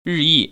日益[ rìyì ]